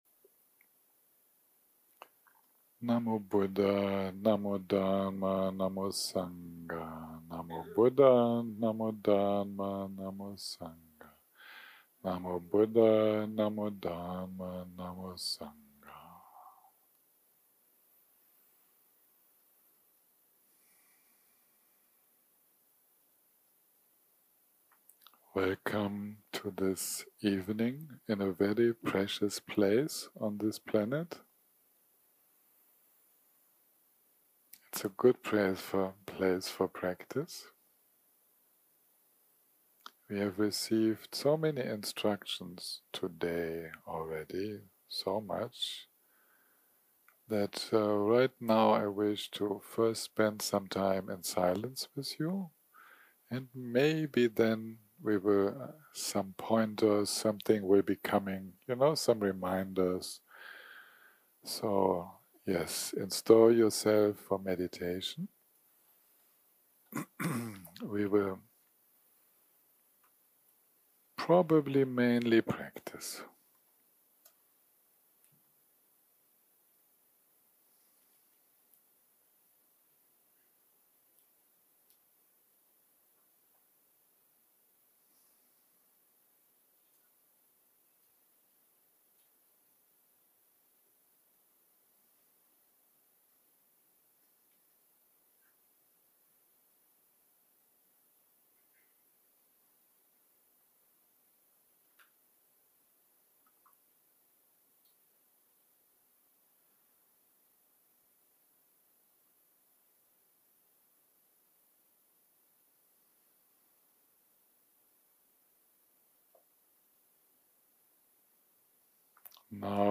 יום 6 - הקלטה 28 - ערב - מדיטציה מונחית
סוג ההקלטה: מדיטציה מונחית